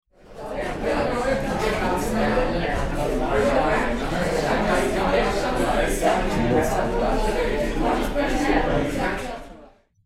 Restaurant Chatter 04
Restaurant_chatter_04.mp3